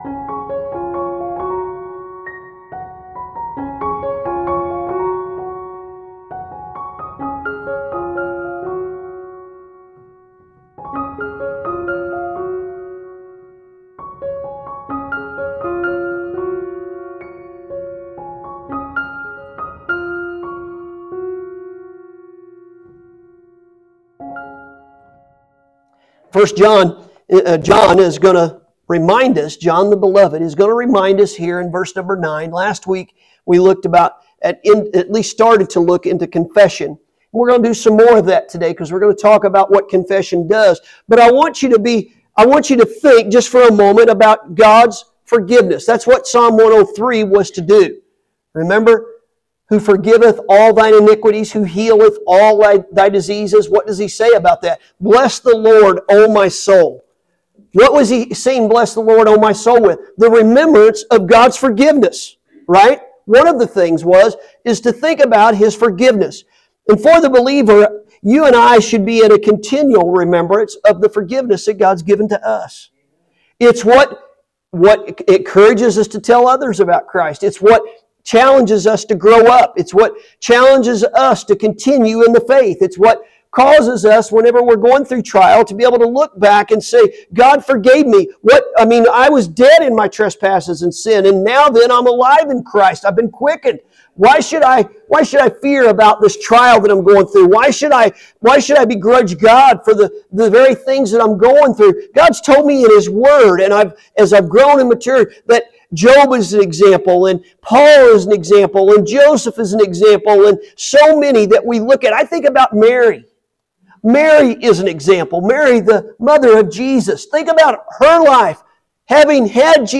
Sunday Morning – October 6th, 2024